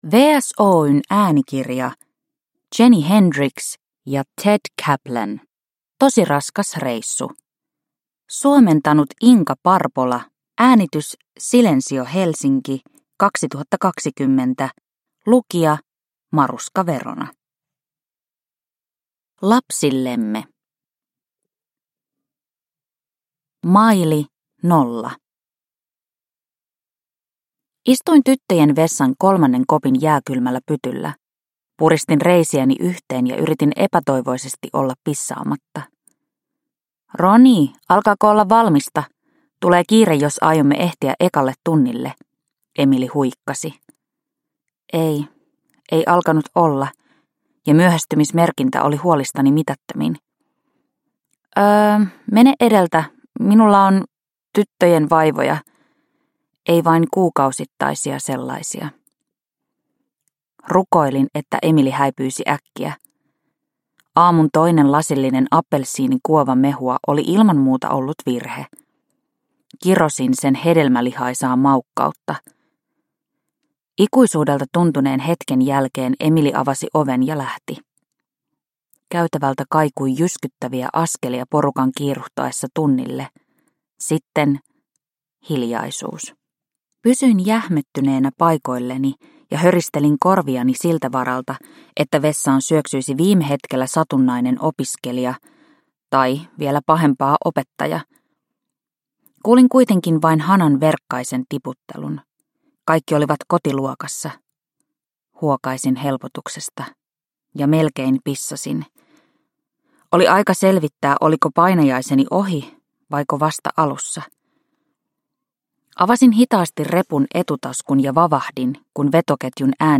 Tosi raskas reissu – Ljudbok – Laddas ner